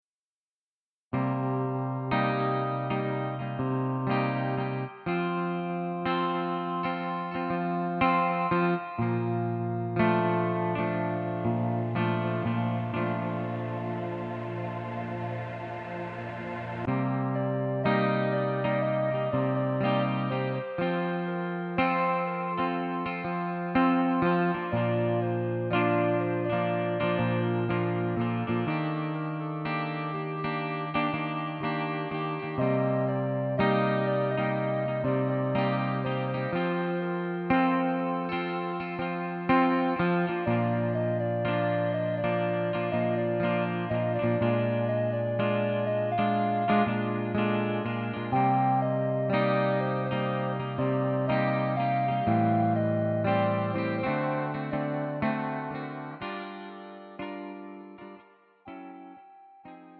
음정 여자키
장르 구분 Pro MR